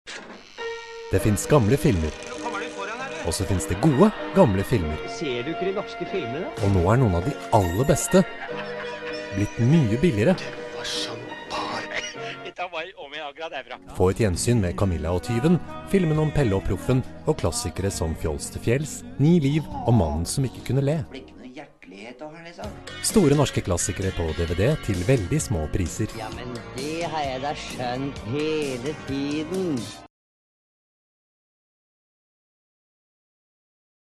norwegischer Sprecher
Kein Dialekt
Sprechprobe: Werbung (Muttersprache):
norwegian voice over artist